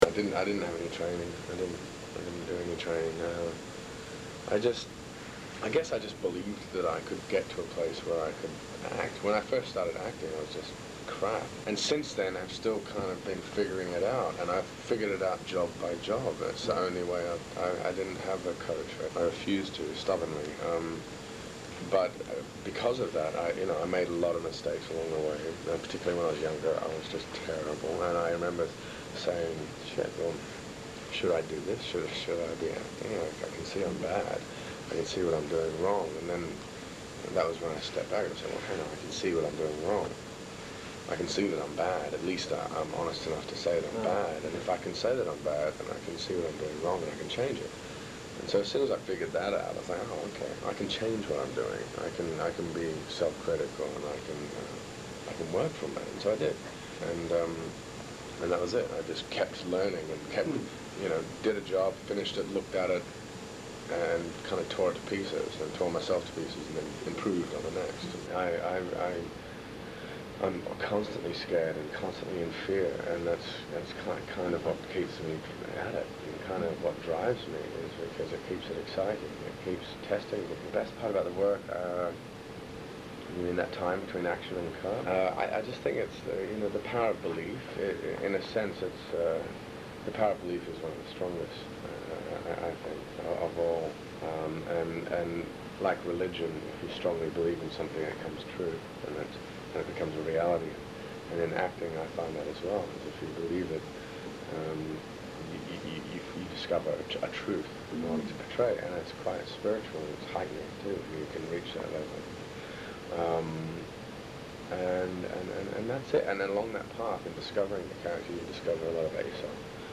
Audio interview
conducted at the Four Seasons Hotel in Beverly Hills.